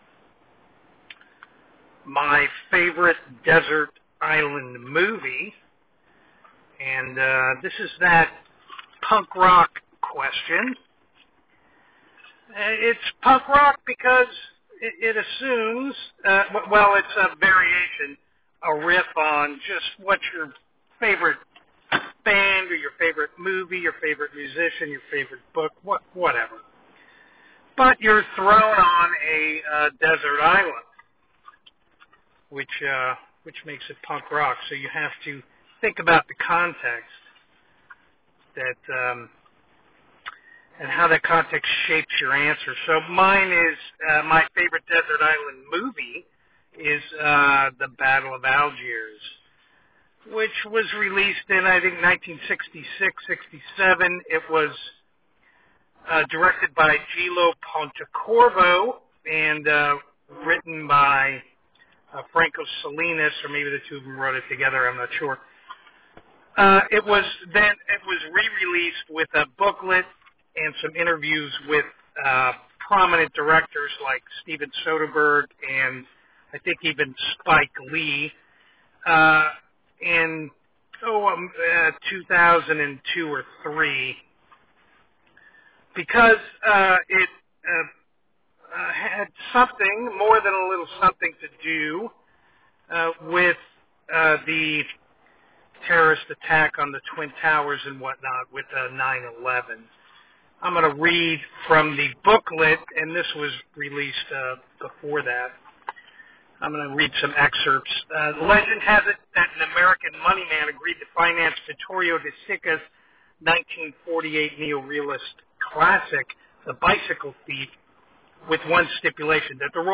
Audio Essay